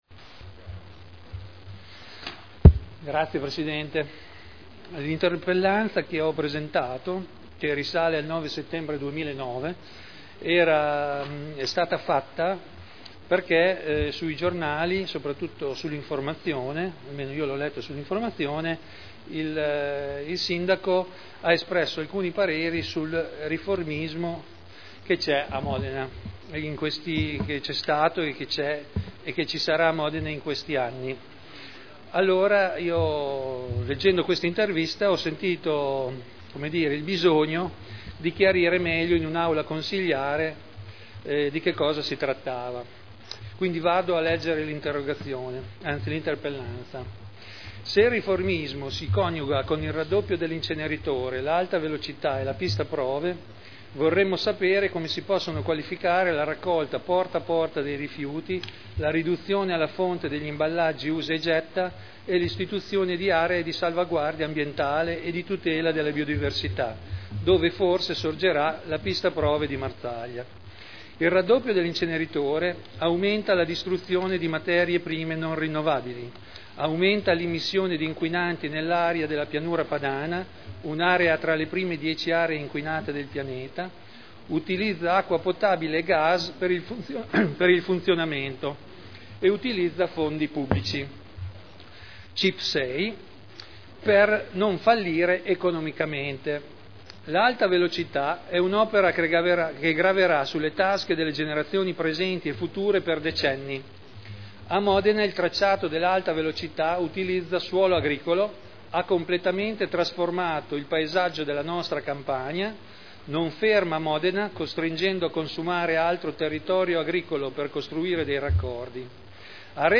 Seduta del 14/01/2010.